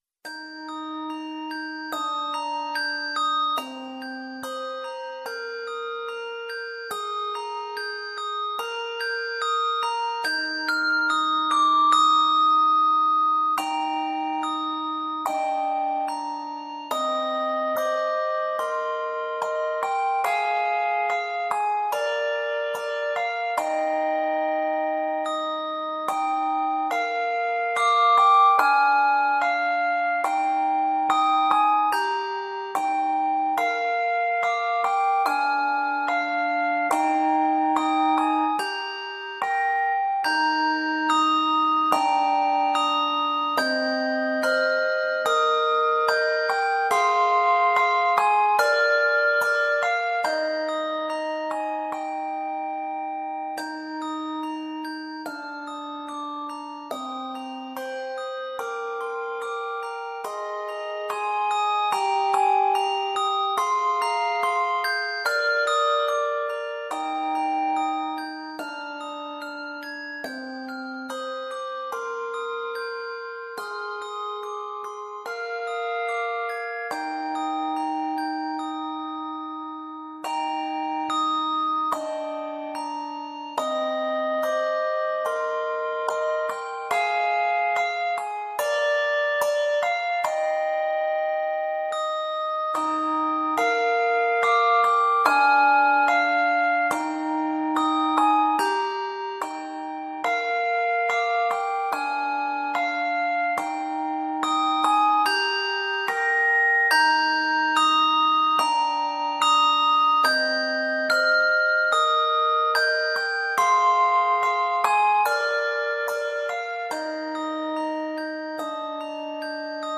Quartet